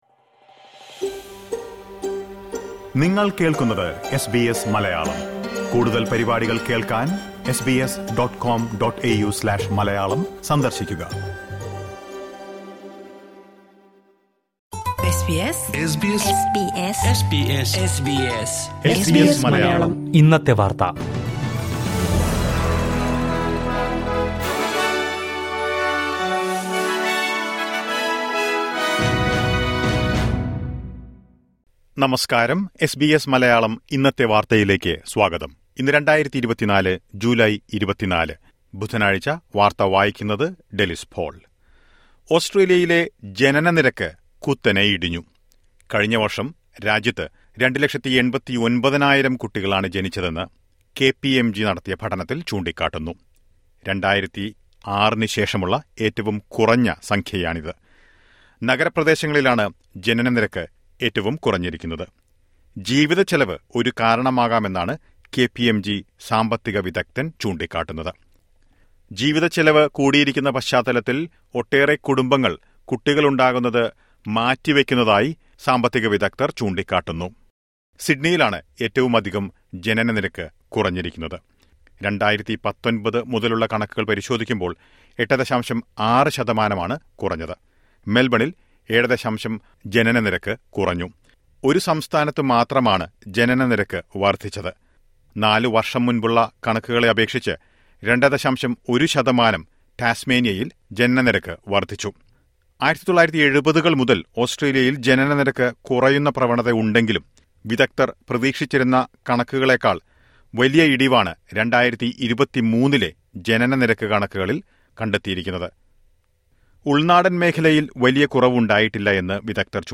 2024 ജൂലൈ 24ലെ ഓസ്‌ട്രേലിയയിലെ ഏറ്റവും പ്രധാന വാര്‍ത്തകള്‍ കേള്‍ക്കാം...